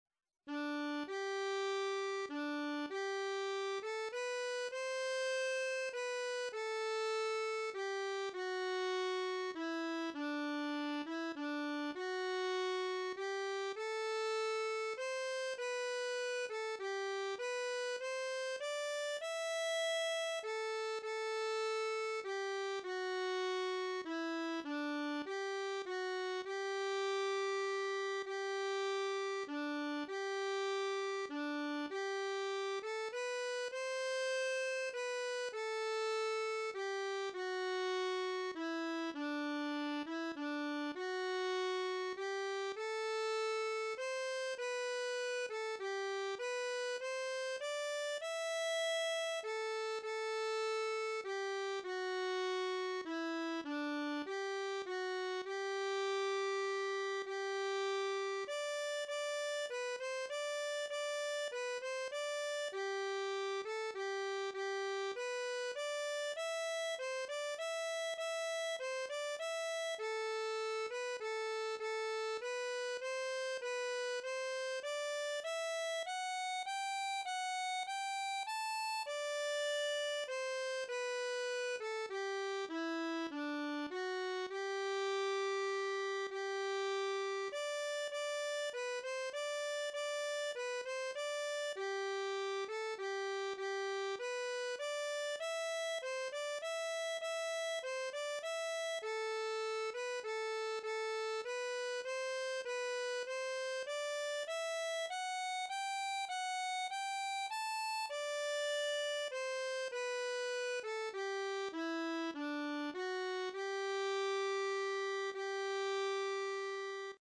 Fanny Power (Waltz)
It is  played now as a waltz, but was originally a "Planxty" -- a song of tribute to O'Carolan's patrons, the Powers.
Slow Version